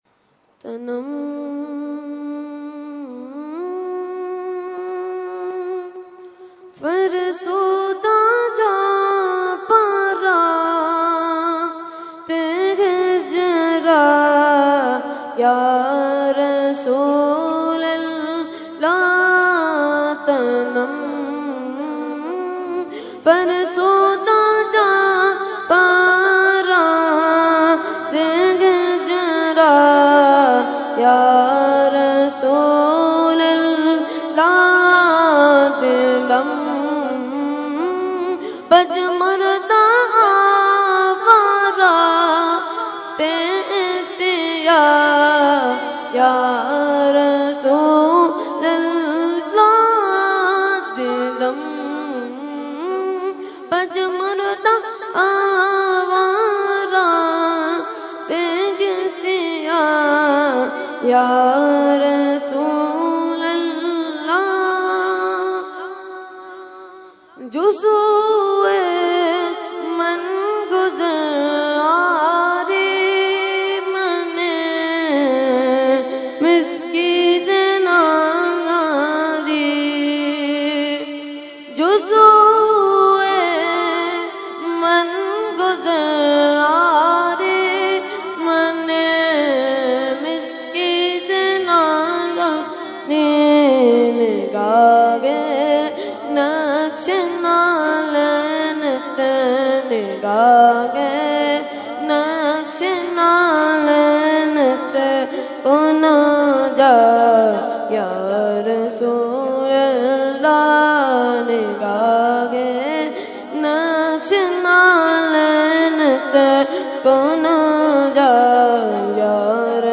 Category : Naat | Language : FarsiEvent : Urs Qutbe Rabbani 2019